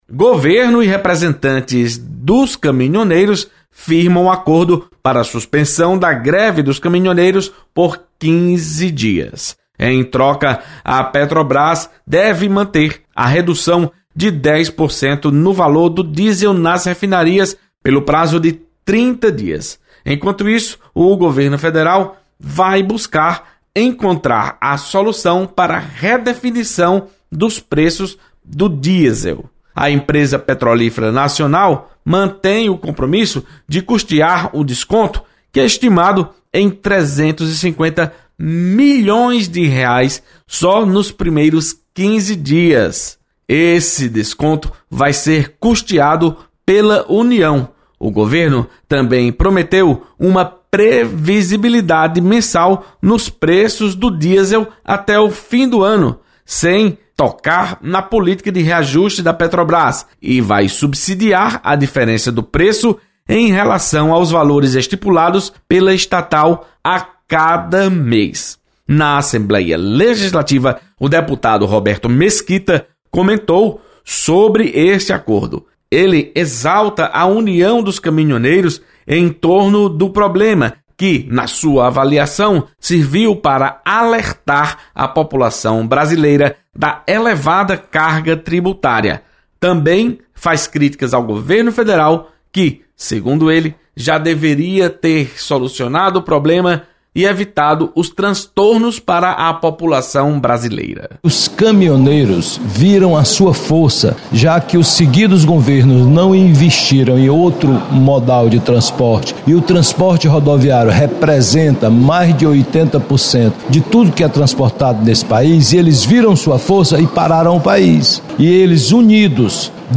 Deputado Roberto Mesquita comenta sobre negociação para por fim à greve dos caminhoneiros.